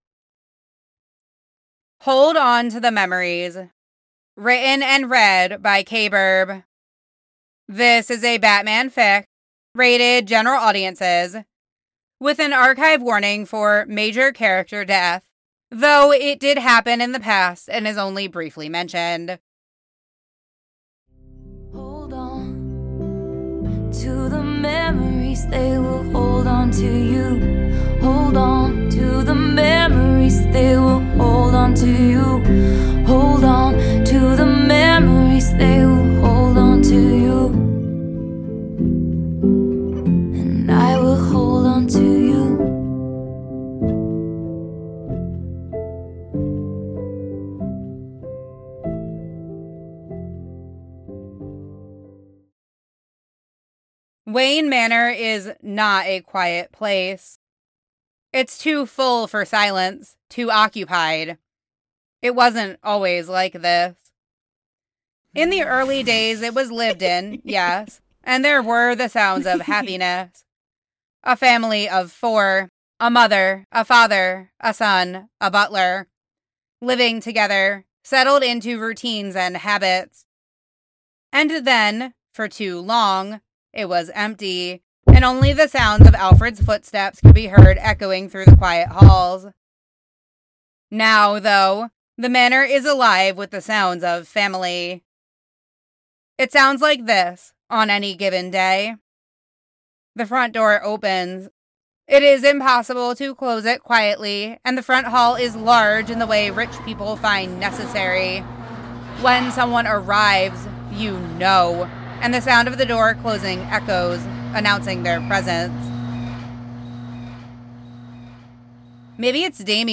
Mismatched Sound Effects Version
SFX provided by multiple podficcers